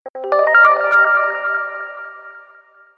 notification.mp3